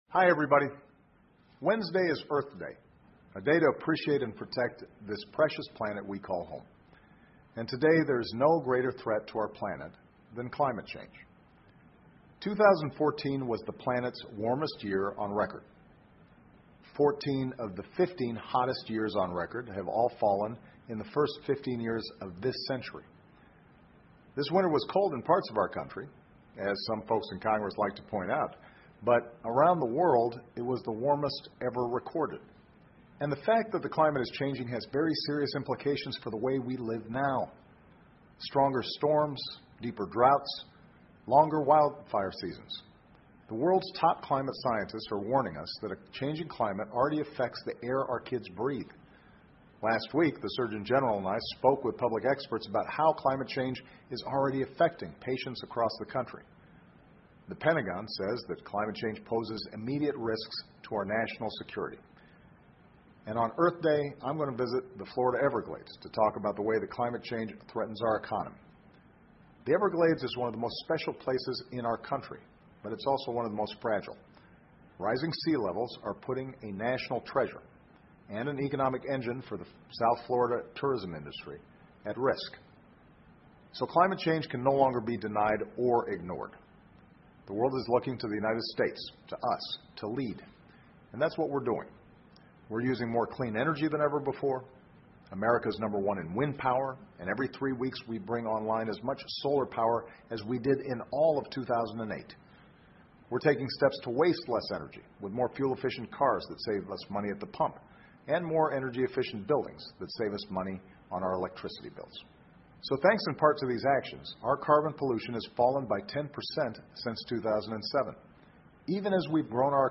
奥巴马每周电视讲话：总统呼吁不能对气候变化再掉以轻心 听力文件下载—在线英语听力室